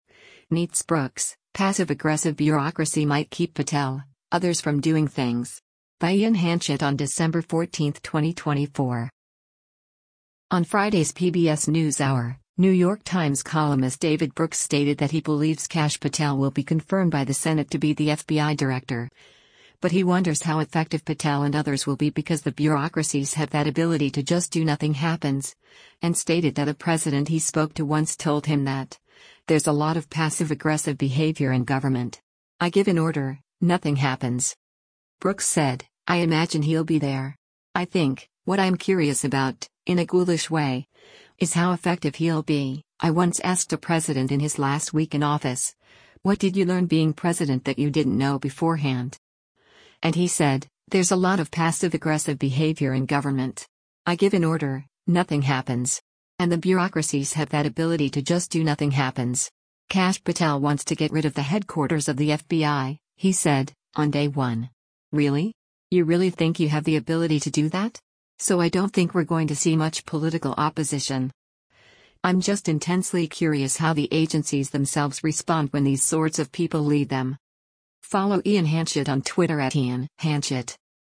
On Friday’s “PBS NewsHour,” New York Times columnist David Brooks stated that he believes Kash Patel will be confirmed by the Senate to be the FBI director, but he wonders how effective Patel and others will be because “the bureaucracies have that ability to just do nothing happens.”